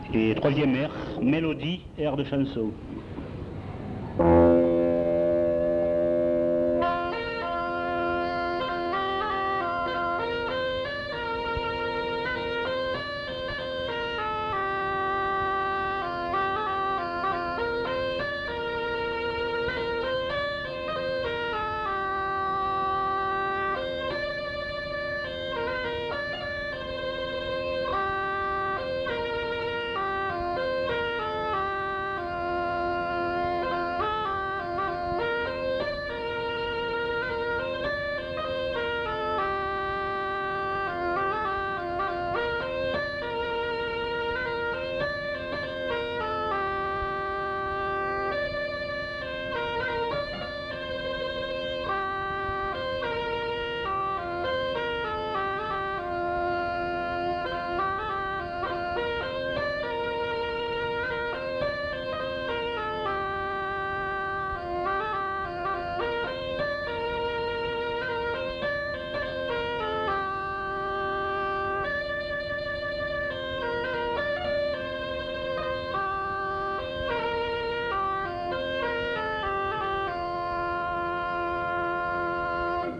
Airs de chansons et de danses interprétés à la bodega
enquêtes sonores